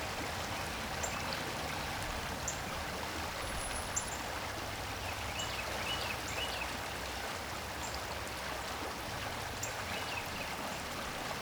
Afternoon Suburban Park Babbling Brook Birds ST450 01_ambiX.wav